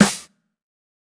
TC2 Snare 2.wav